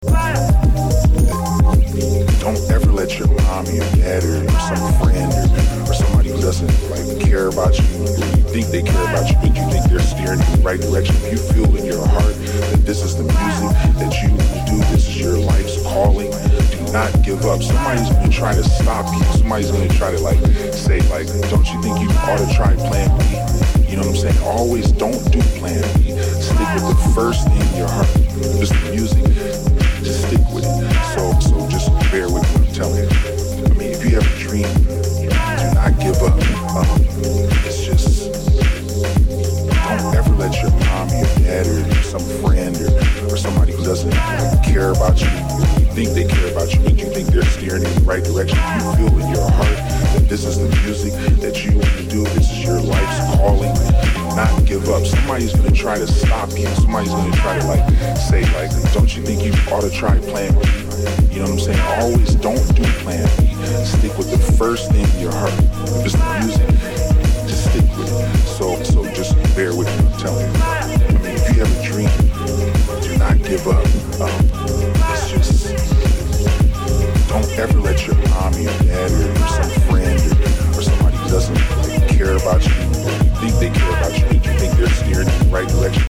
This is dark!